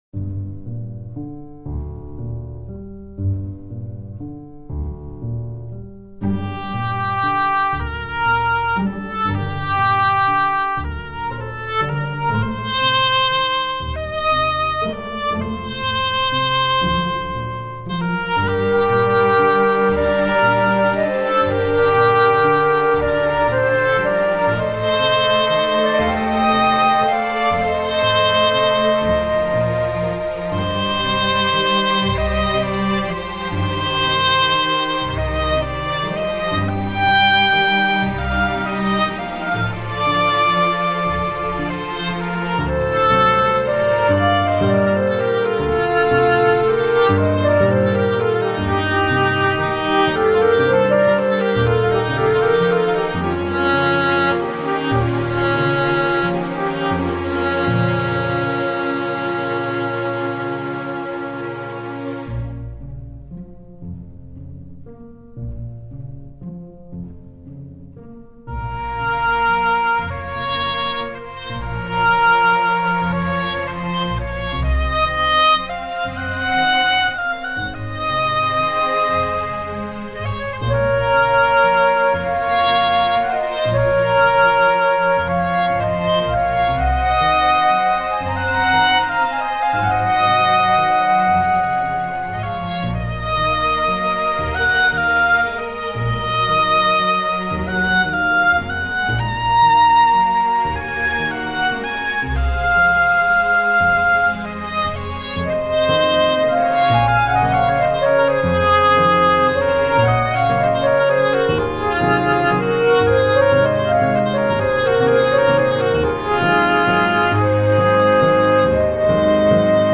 Another waltz!